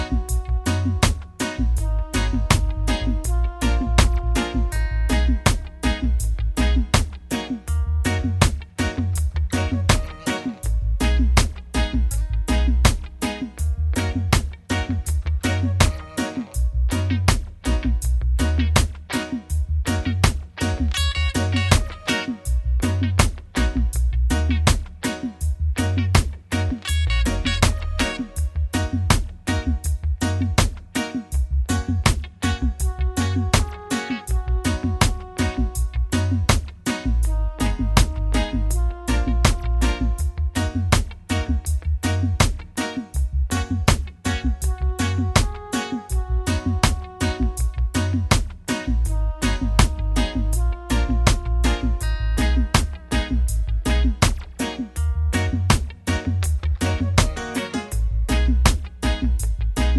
Minus Main Guitar For Guitarists 3:53 Buy £1.50